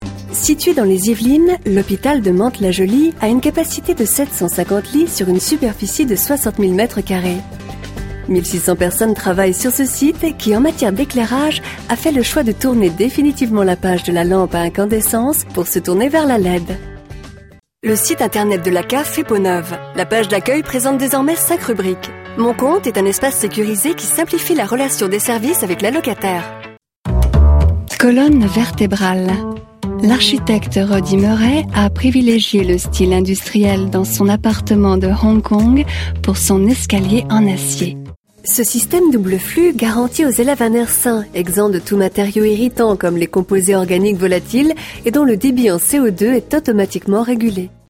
Mon timbre de voix médium (hertzien) qui me permet de passer d'un ton du plus institutionnel, à un ton classe, souriant, sérieux, rassurant,sensuel convaincant, chaleureux, doux, ravissante idiote...jusqu'aux voix jouées!
Sprechprobe: Industrie (Muttersprache):
My stamp of voice medium which allows me to pass of a tone of the most institutional, in a classy, smiling, serious, reassuring, convincing, warm, soft tone, charming stupid until the played voices!